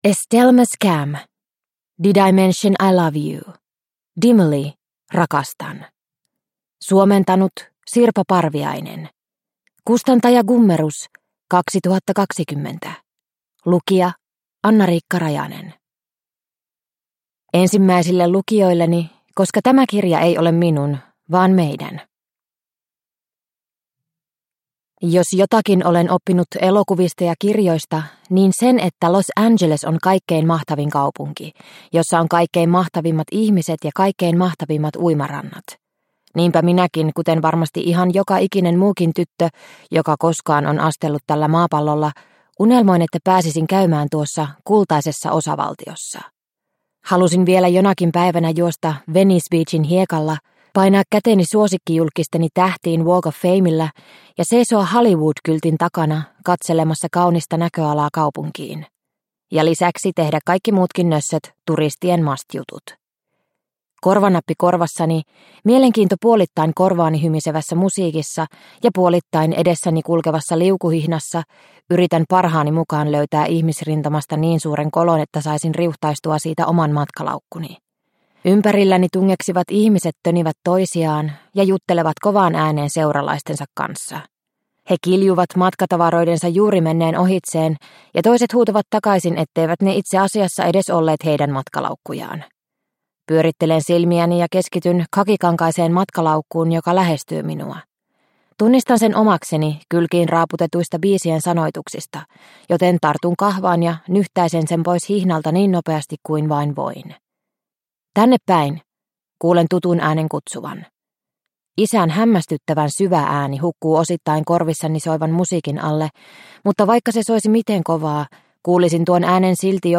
DIMILY - Rakastan – Ljudbok – Laddas ner